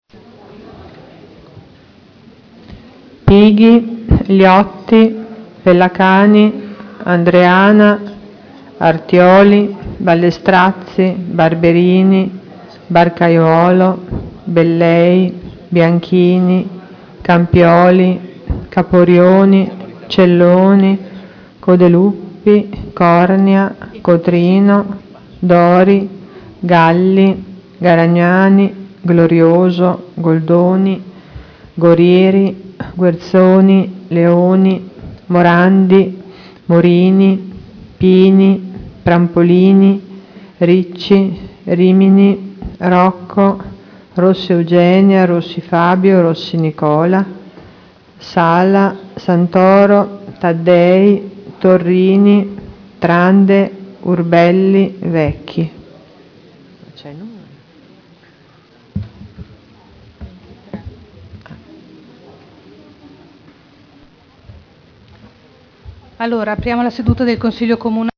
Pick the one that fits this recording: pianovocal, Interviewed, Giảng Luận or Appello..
Appello.